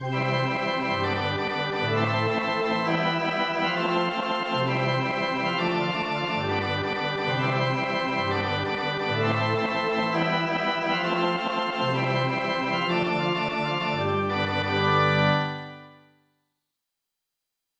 MPEG ADTS, layer III, v2, 128 kbps, 16 kHz, Monaural